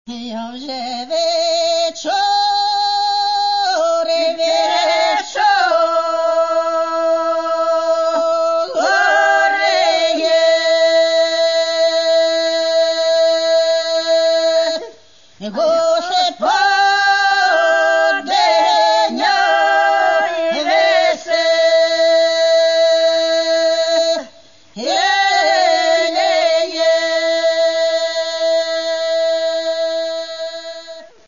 Каталог -> Народна -> Автентичне виконання